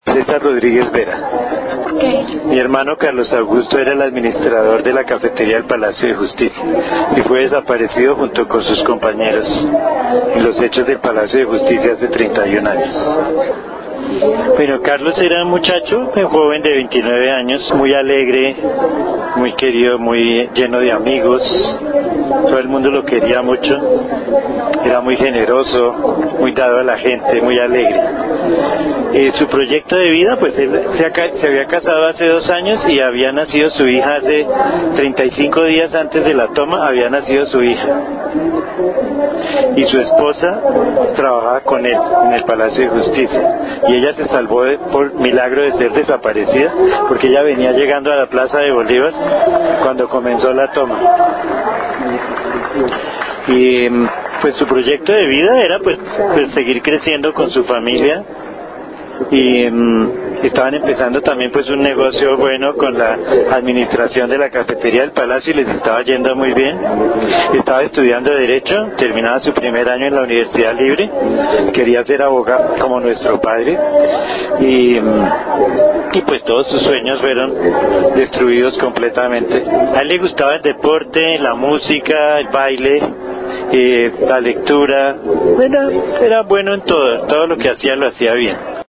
A través de las palabras y voces de los  familiares de las víctimas, recordamos a cada uno de los desaparecidos del Palacio de Justicia: